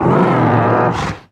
Cri de Bourrinos dans Pokémon Soleil et Lune.